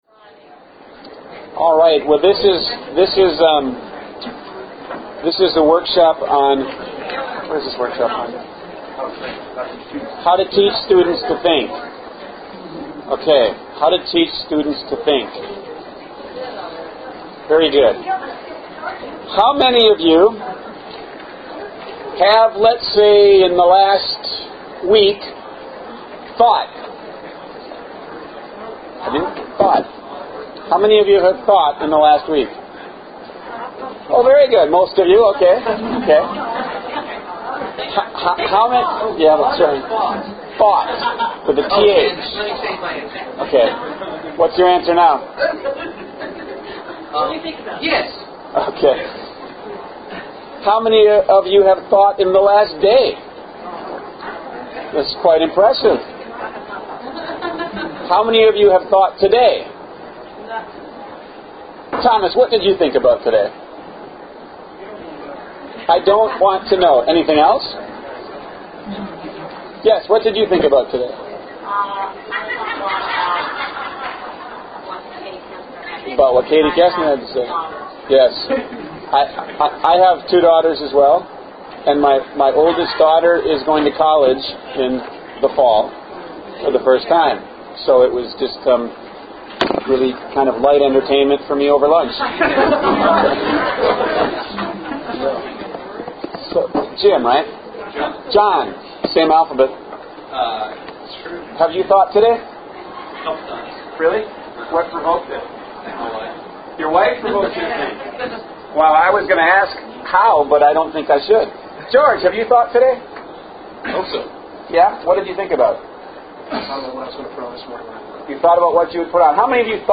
But with the right structures and the right spirit, kids can be taught this basic human skill – at a very high level. This workshop discusses how and offers examples.